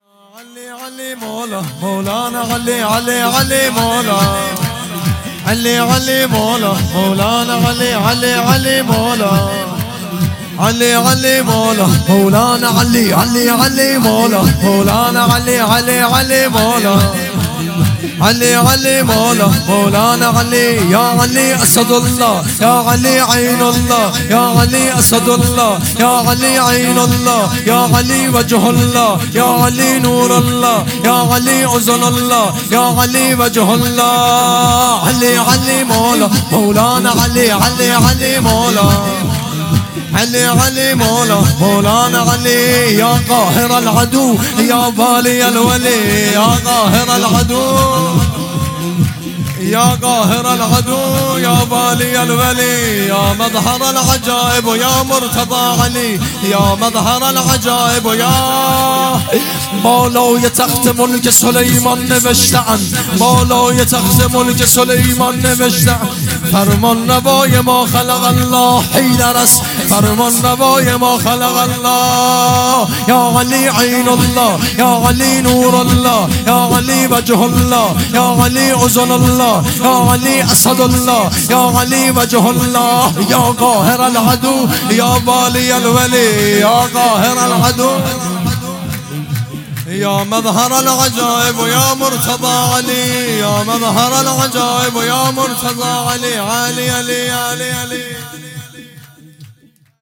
مولودی
جشن میلاد حضرت امیر المومنین(ع)